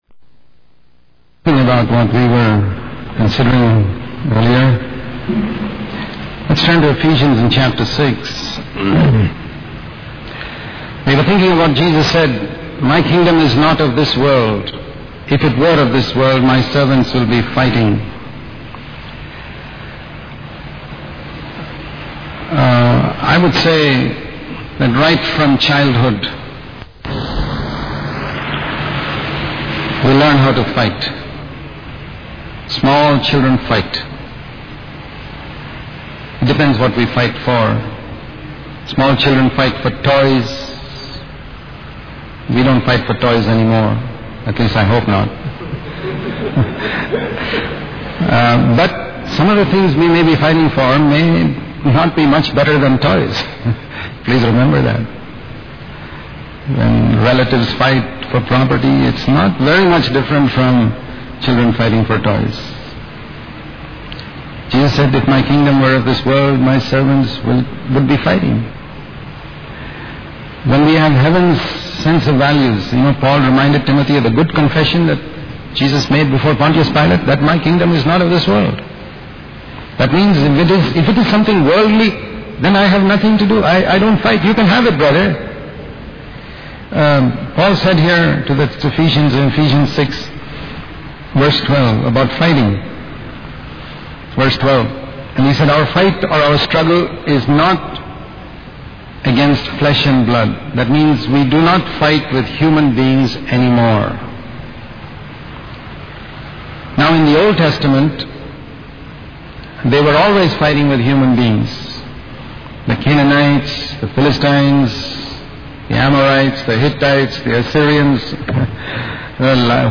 In this sermon, the speaker emphasizes the importance of learning to trust the Lord and standing on our own feet. He uses the analogy of being carried on a stretcher versus learning to walk and trust God.